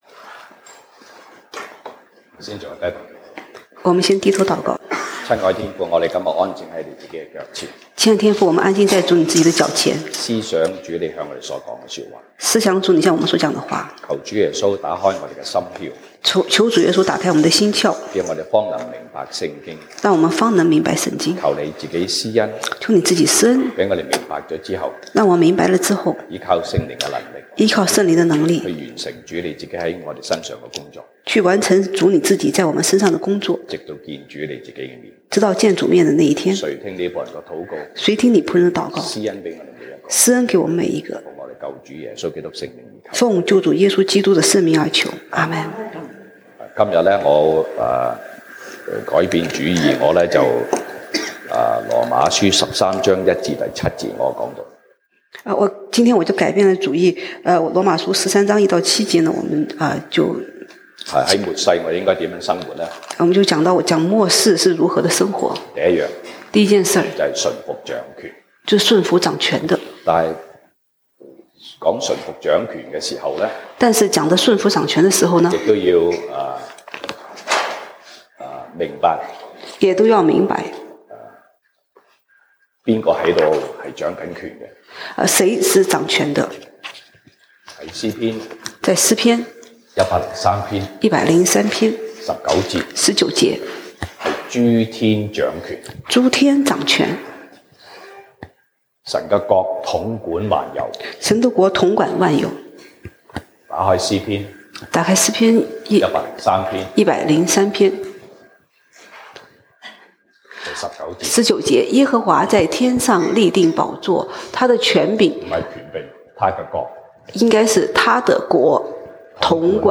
西堂證道(粵語/國語) Sunday Service Chinese: 在末世如何生活？
羅馬書 Romans 13:1-7 Service Type: 西堂證道(粵語/國語) Sunday Service Chinese Topics: 在末世如何生活？